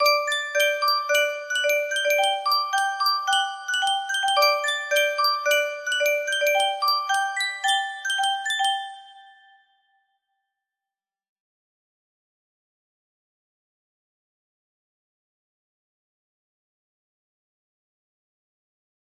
ut msbox music box melody
Full range 60